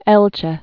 (ĕlchĕ)